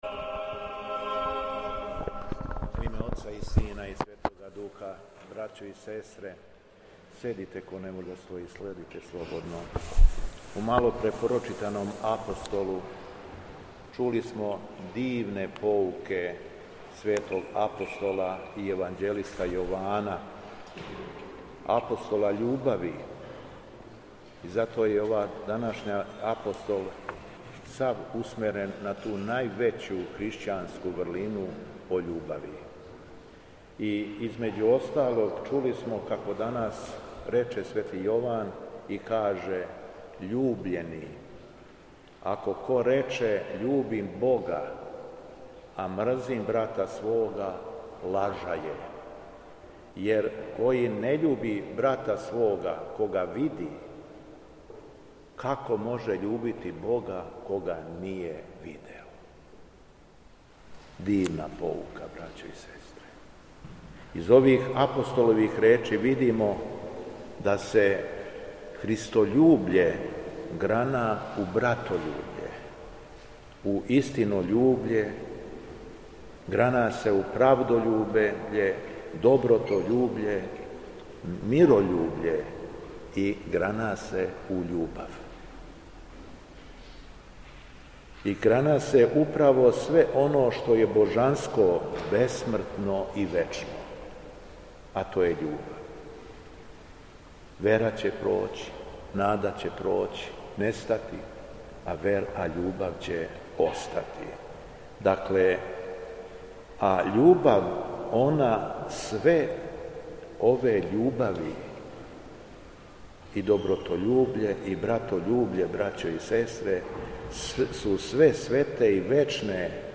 Беседа Његовог Високопреосвештенства Митрополита шумадијског г. Јована
Седница Савета, на којој су разматрани домети црквеног живота у Шумадијској епархији током 2024. године, одржан је у Владичанском двору у Крагујевцу.